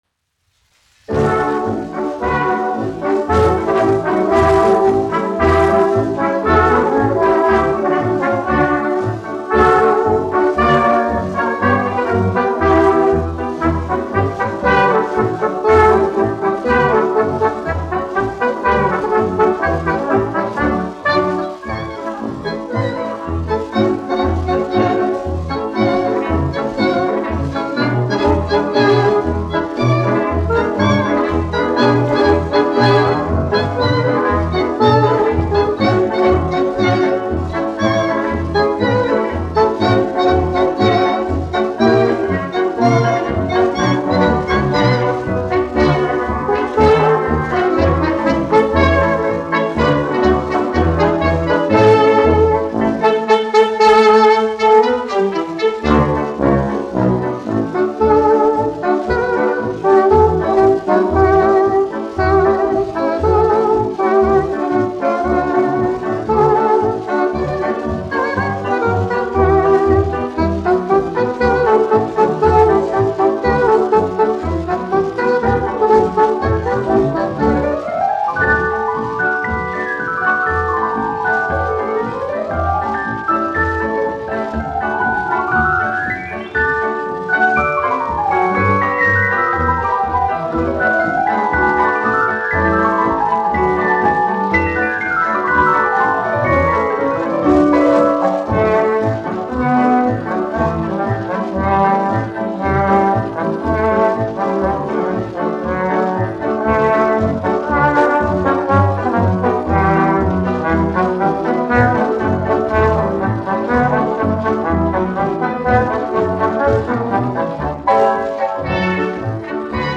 1 skpl. : analogs, 78 apgr/min, mono ; 25 cm
Marši
Populārā instrumentālā mūzika
Skaņuplate